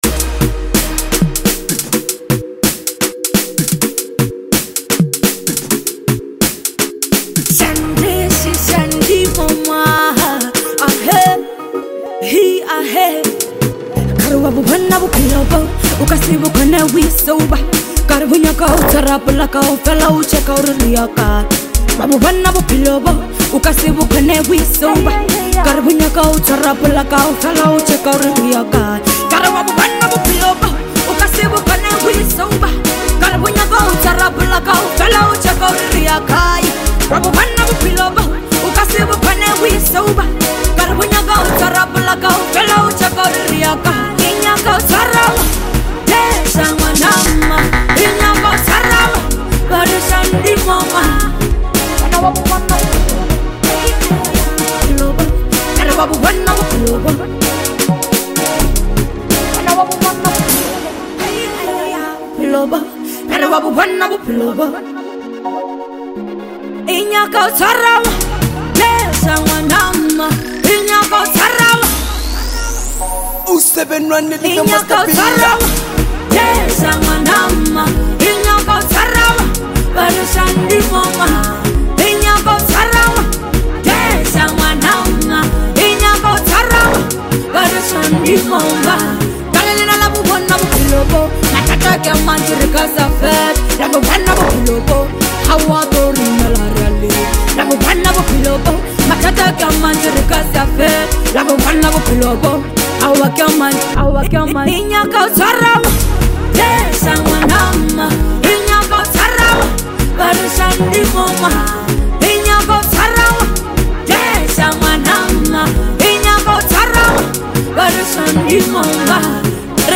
is an electrifying anthem